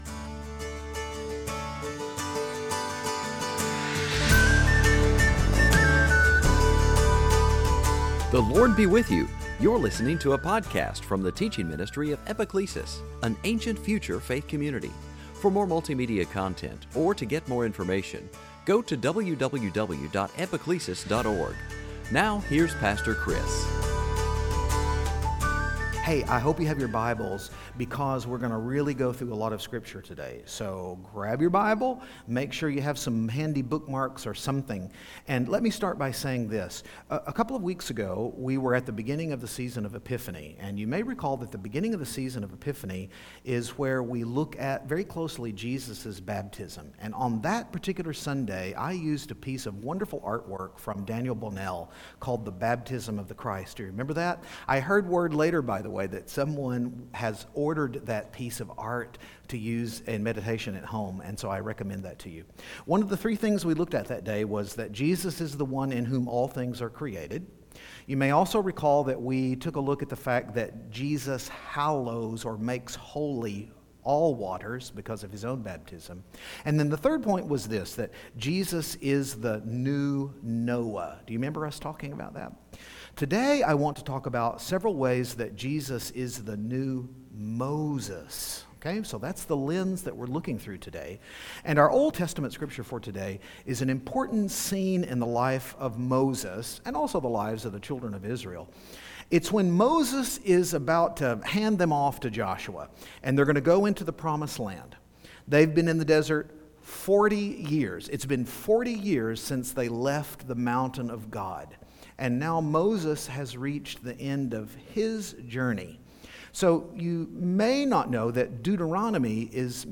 2021 Sunday Teaching blood bread Jesus Lamb Manna Moses new Moses New Noah Passover Transfiguration Epiphany